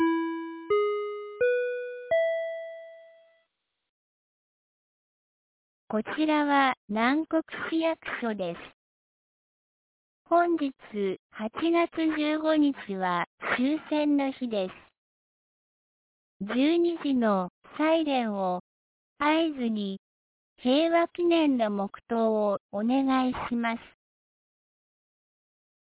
2025年08月15日 11時59分に、南国市より放送がありました。